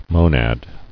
[mo·nad]